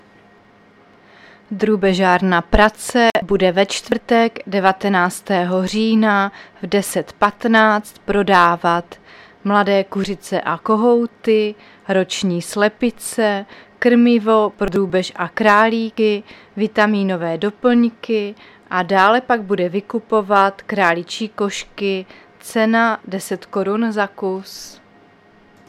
Záznam hlášení místního rozhlasu 16.10.2023
Zařazení: Rozhlas